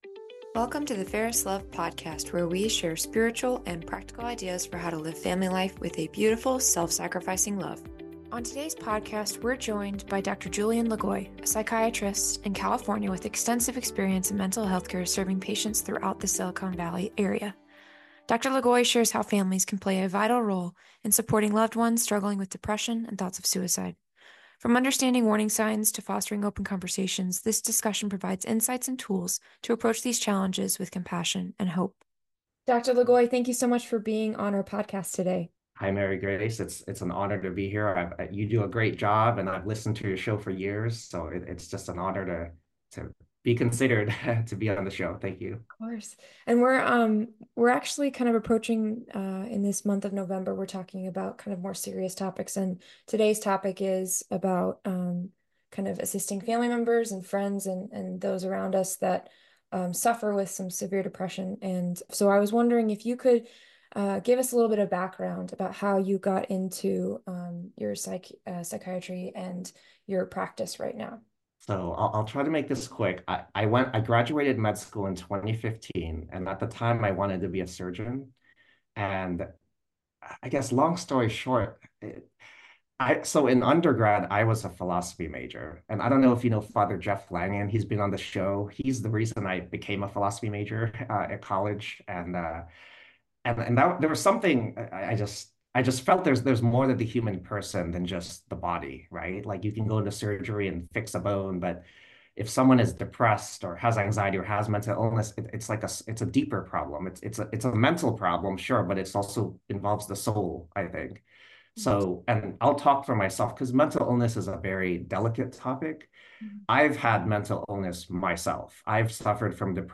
As we continue discussing the topic of grief this month, we had the opportunity to interview psychiatrist